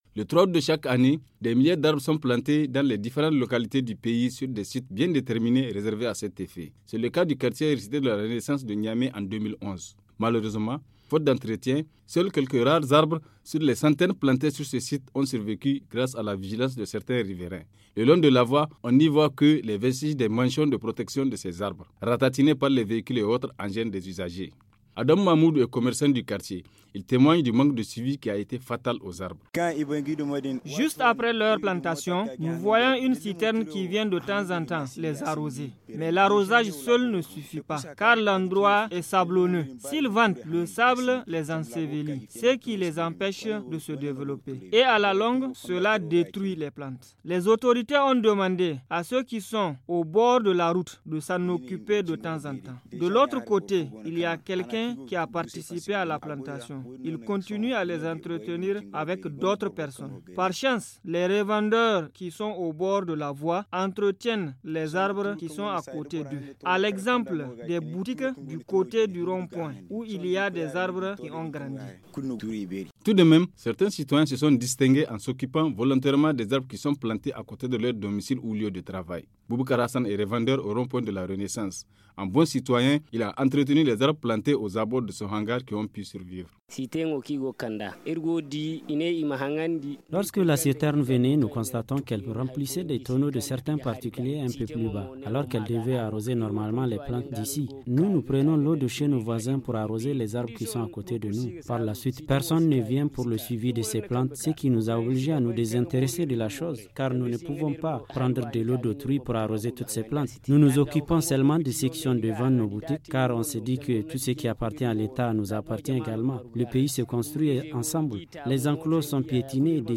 Voic son reportage.